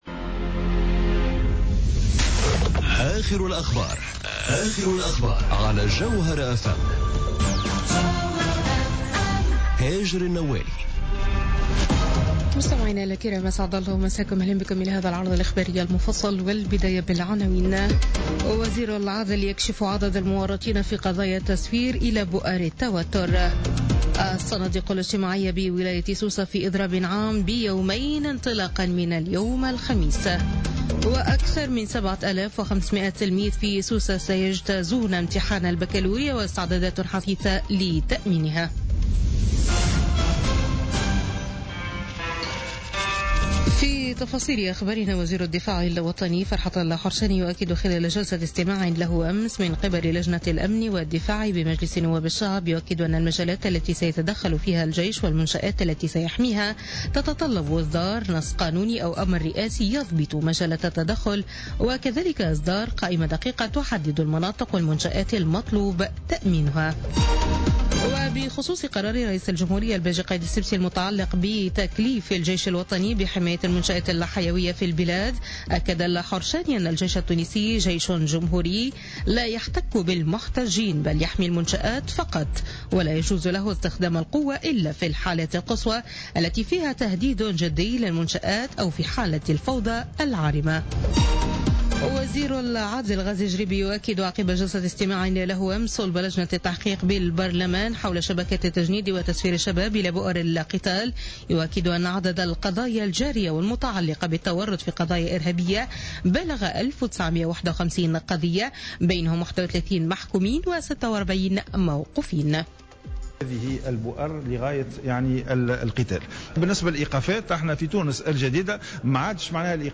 نشرة أخبار منتصف الليل ليوم الخميس 18 ماي 2017